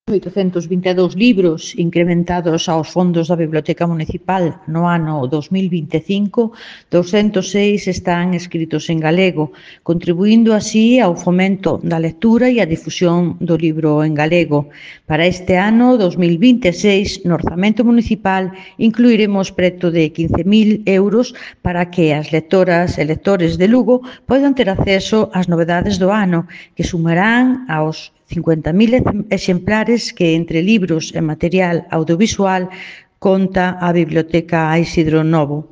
Audio La Concejala de Cultura, Turismo y Promoción de la Lengua, Maite Ferreiro, sobre la biblioteca municipal | Descargar mp3